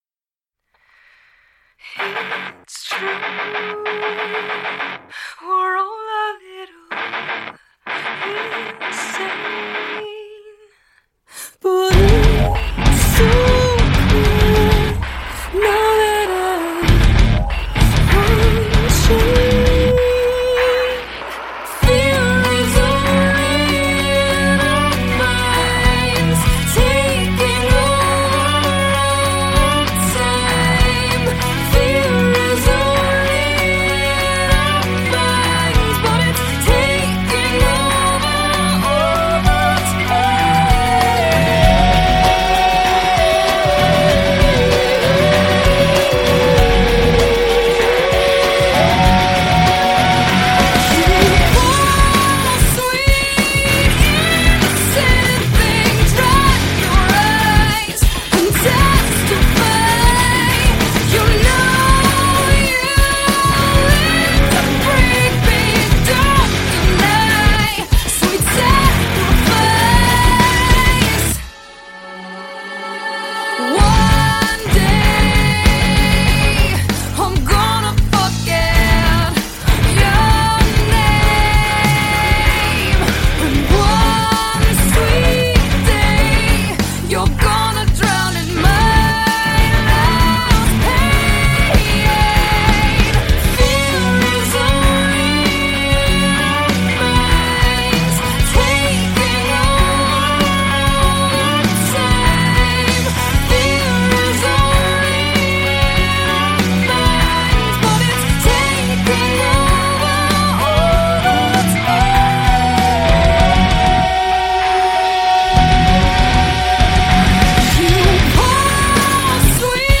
Жанр: Alternative, Gothic Metal